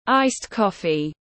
Cà phê đá tiếng anh gọi là ice coffee, phiên âm tiếng anh đọc là /ˌaɪst ˈkɒf.i/
Ice coffee /ˌaɪst ˈkɒf.i/